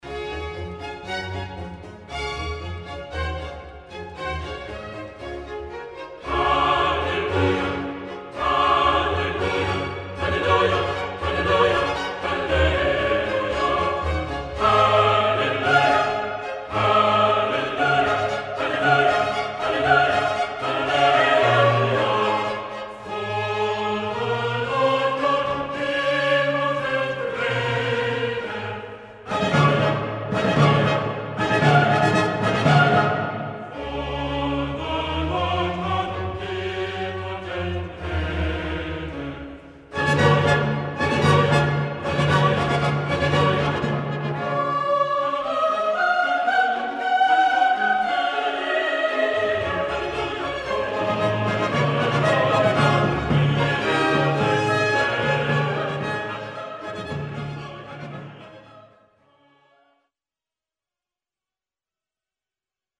オラトリオ《メサイヤ》HWV56「Hallelujah!」（ハレルヤ）